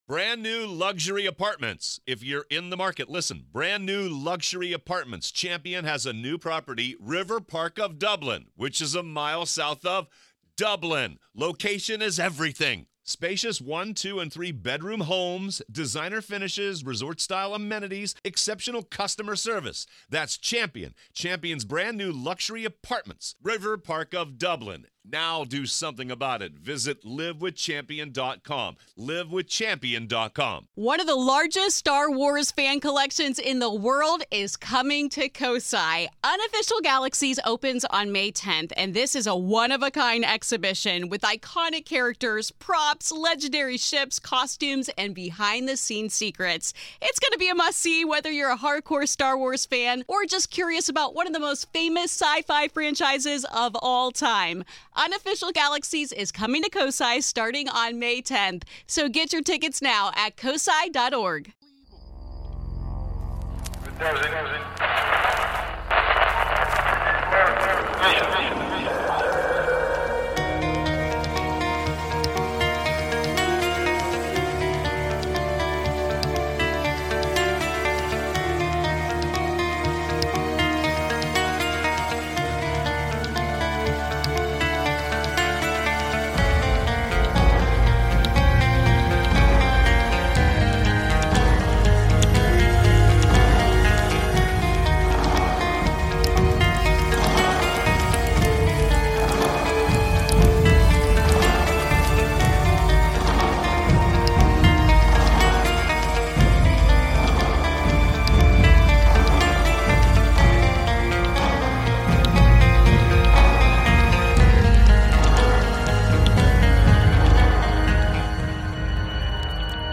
On this episode I play calls about UFO's in Mexico, a ghostly child in a mall, space snakes and a hitchhiking pioneer.